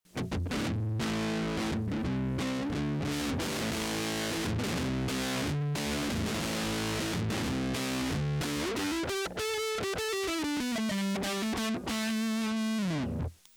Samples were made using a Fender Stratocaster with Lollar S Series single coil pickups and a Fender Concert amp. Neck pickup selected with volume and tone controls at maximum. Standard tuning. The amp tone controls are set to 12 o’clock with the reverb turned off.
Fuzz Dynamic
A basic fuzz sound. The fuzz will respond to the input’s volume cleaning up when turned down.